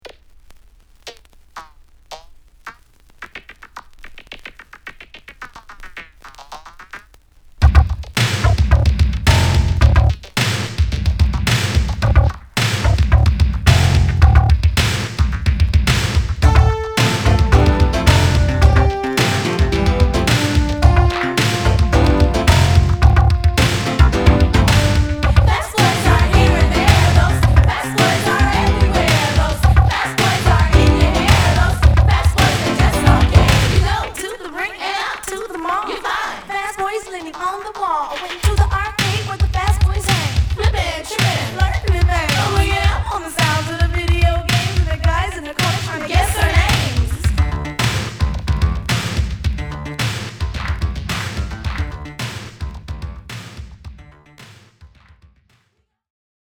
Rare 80's LA Electro Hip Hop!!